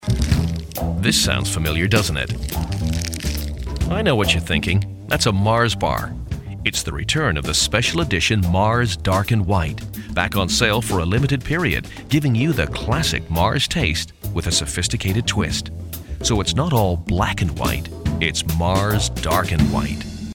Male, 40s, 50s, 60s, DJ, American, The Kid, commercial, advert, voiceover, voice over, DGV, Damn Good Voices, damngoodvoices, Crying Out Loud, cryingoutloud,